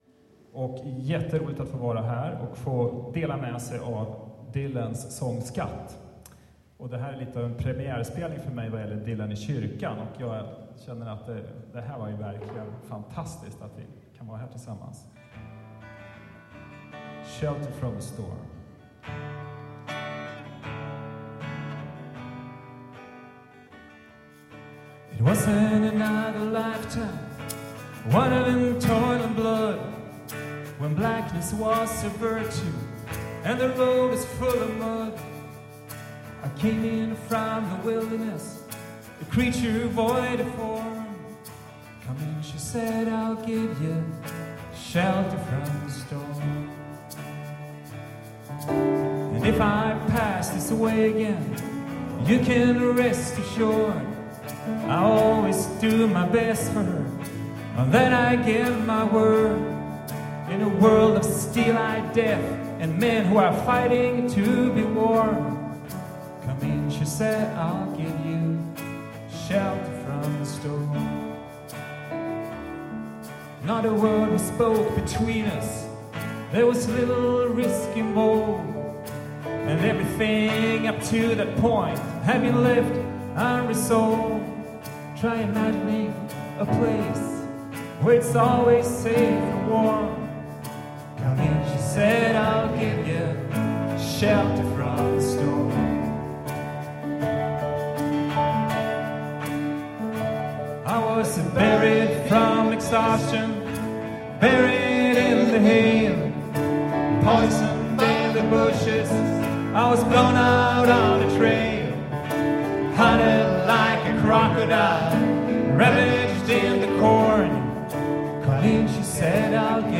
Denna web-sida innehåller musik från konserter och